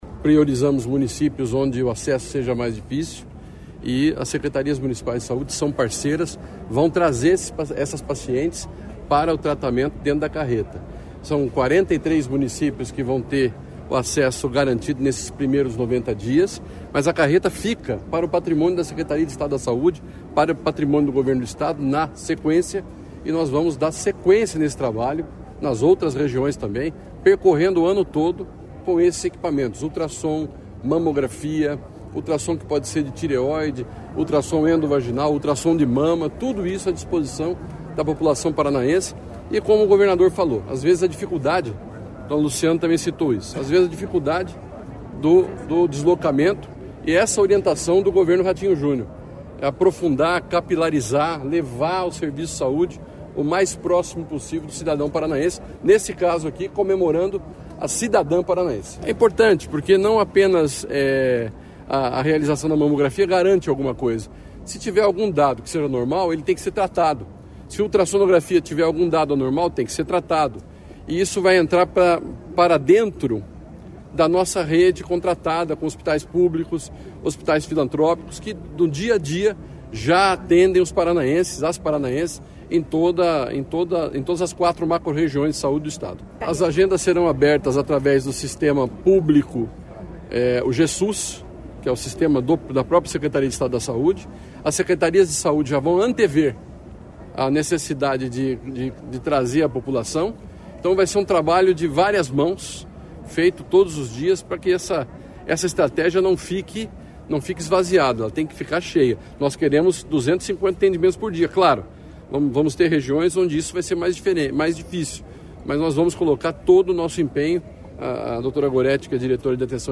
Sonora do secretário Estadual da Saúde, Beto Preto, sobre o lançamento da Carreta Saúde da Mulher